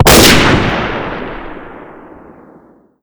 spring_shoot.wav